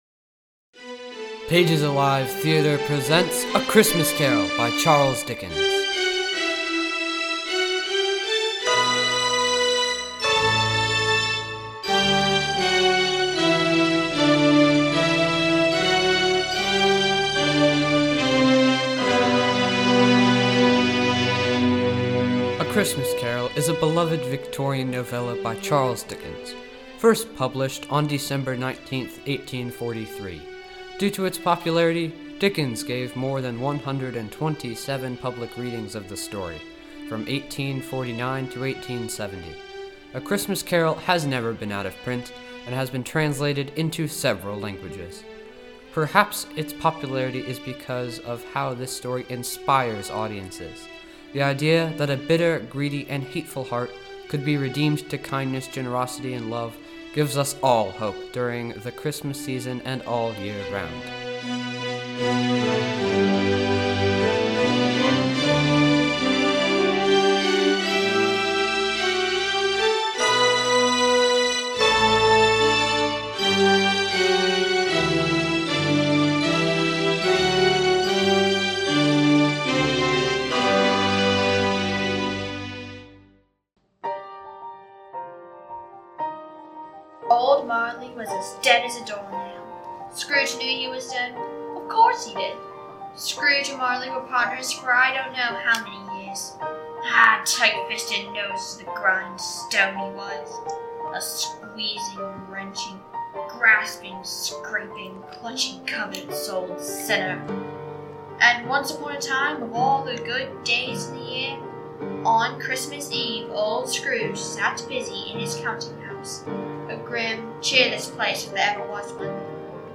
a-christmas-carol-audio-drama-by-pages-alive-theater.mp3